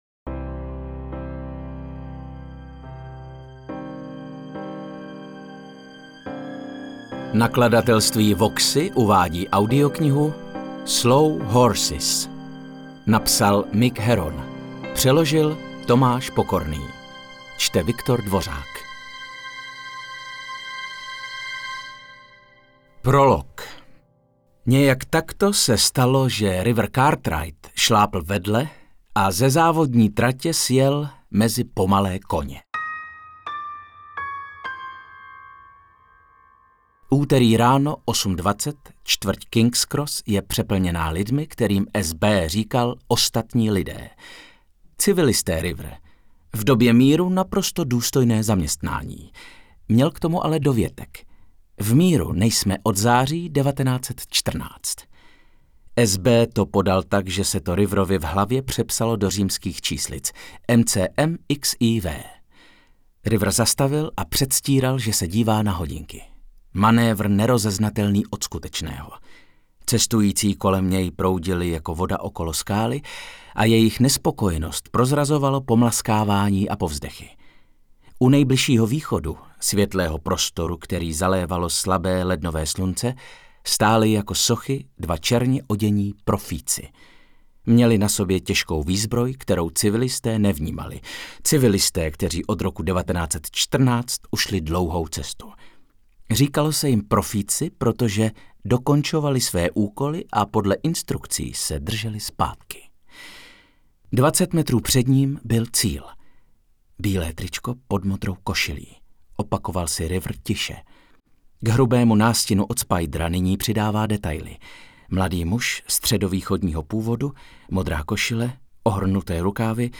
Interpret:  Viktor Dvořák
AudioKniha ke stažení, 31 x mp3, délka 11 hod. 29 min., velikost 1574,5 MB, česky